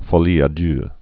(fô-lē ä dœ, fŏlē)